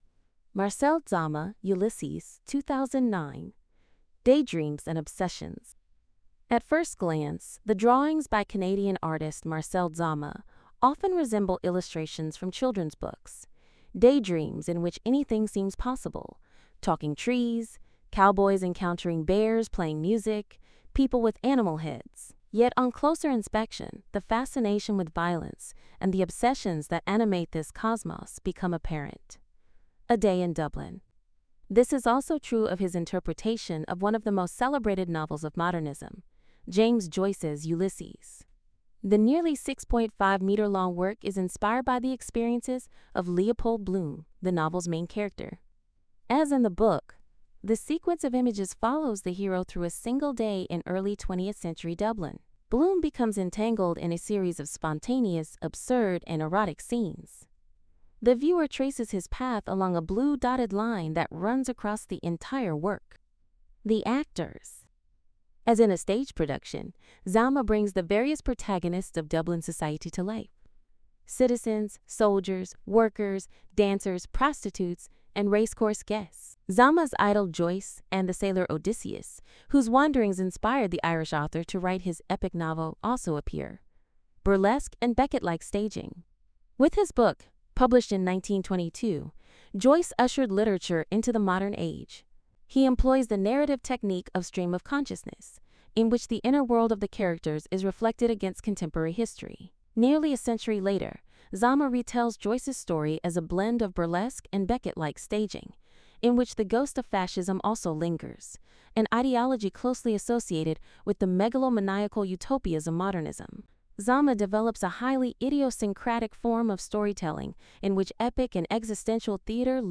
Hinweis: Die Audiotranskription ist von einer KI eingesprochen.